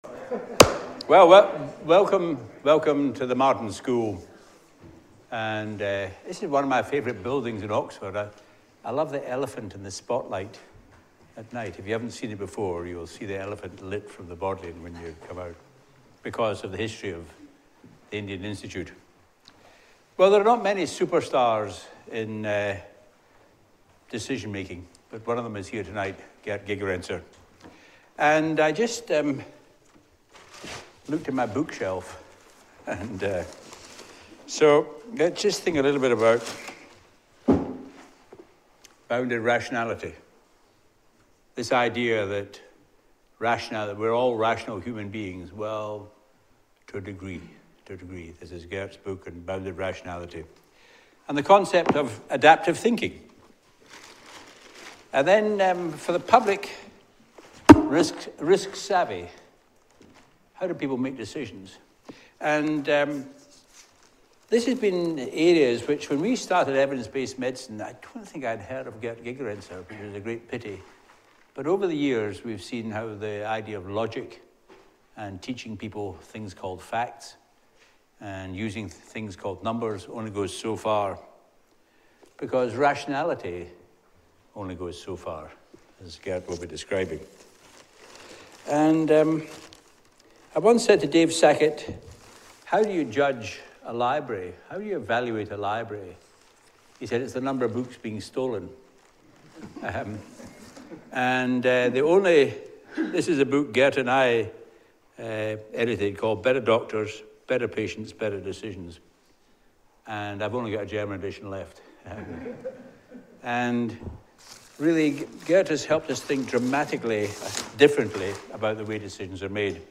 Public Lectures and Seminars